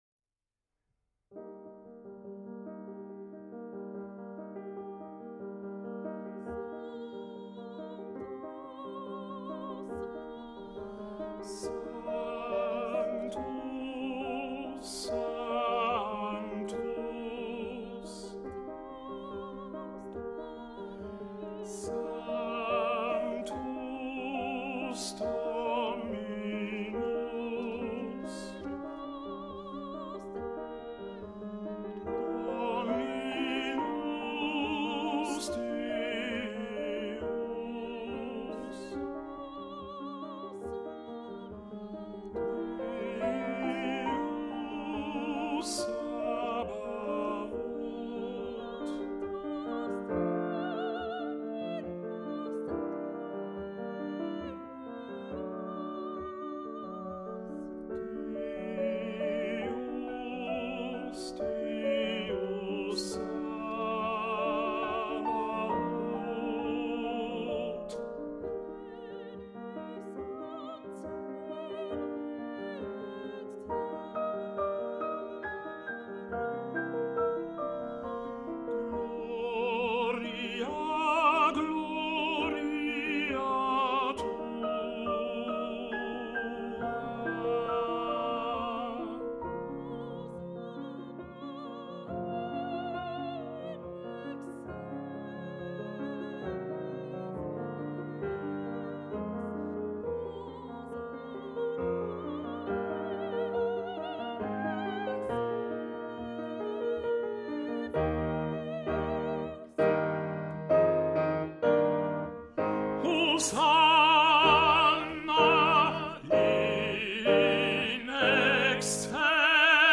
sanctus tenors amplifies